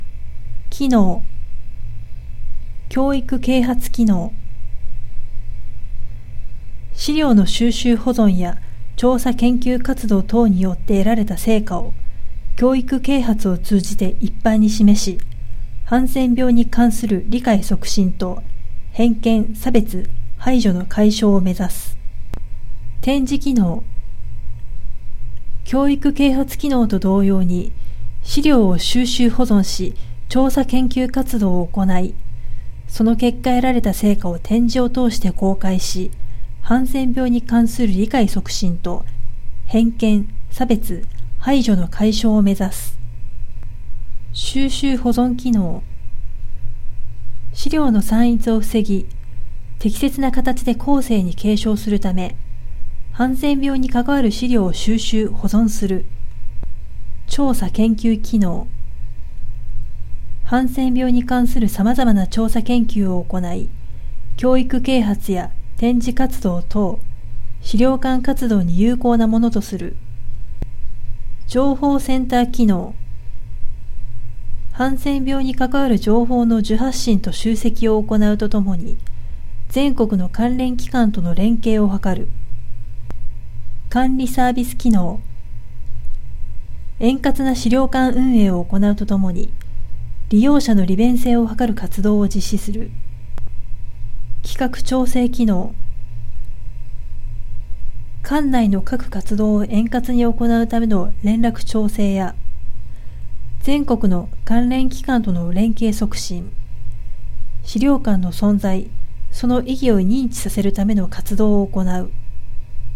音声アナウンス